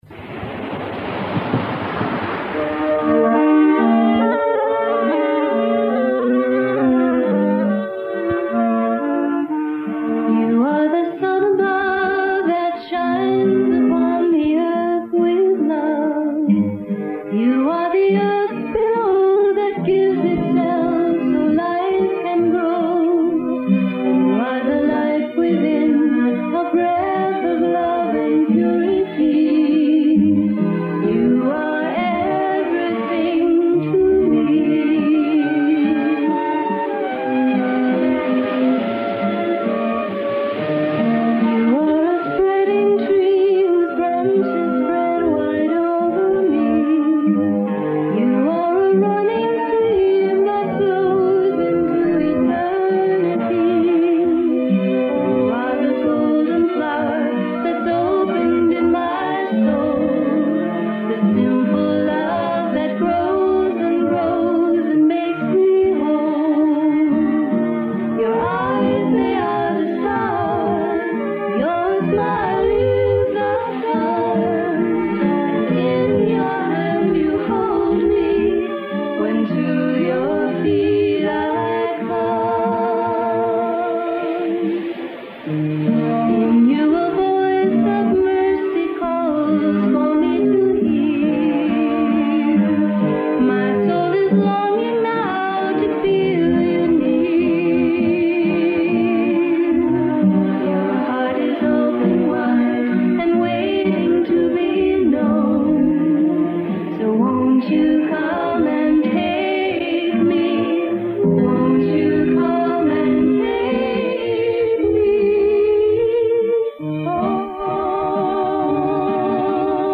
These audio clips were made from a copy of the "Wedding Video" that was overlooked during the destruction of Divine Light Mission publications in the early 1980's. Unfortunately it was later destroyed by a zealous "student" of Prem Rawat's.
NB: the actual wedding ceremony probably did not include a background soundtrack